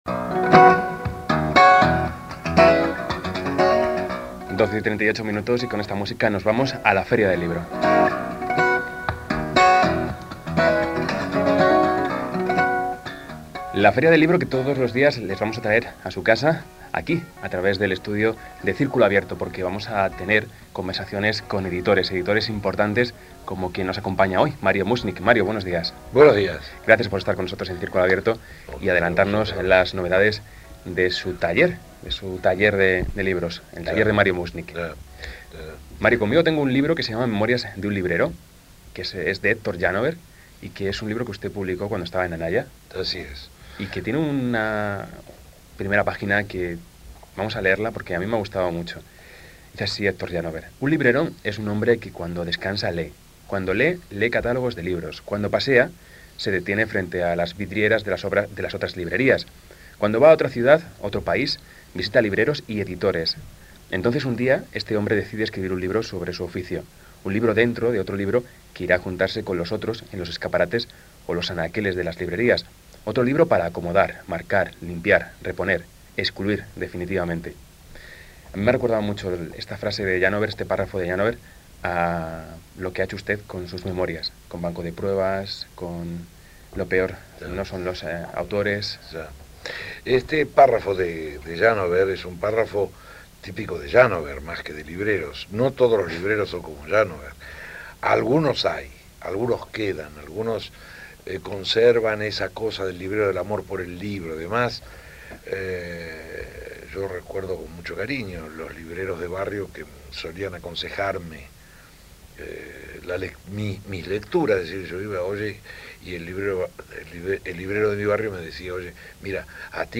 Mario Muchnik llegó al estudio sudoroso y agitado, como si hubiera subido en diez zancadas los cinco pisos del Círculo de Bellas Artes.
entrevista-a-mario-muchnik.mp3